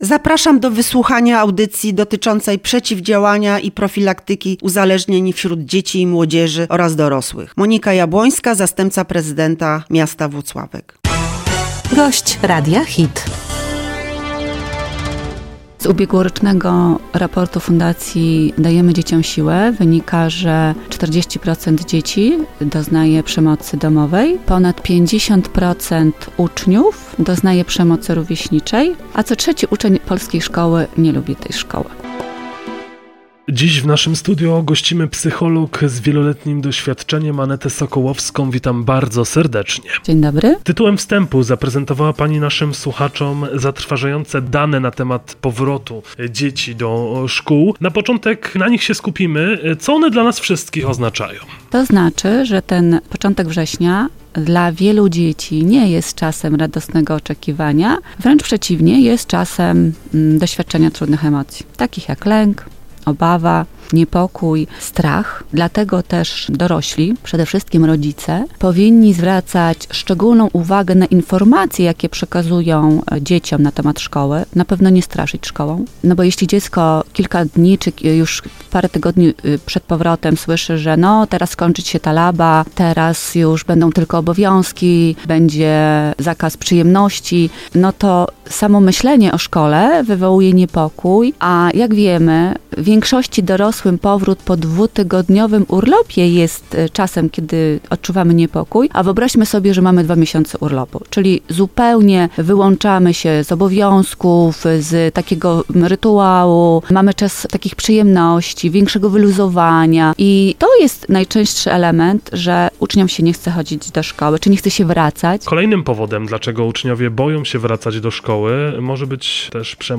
WYWIAD
Powracamy do Cyklu Audycji Informacyjno-edukacyjnych o tematyce profilaktycznej w ramach Miejskiego Programu Profilaktyki i Rozwiązywania Problemów Alkoholowych i Przeciwdziałaniu Narkomani w 2024 roku.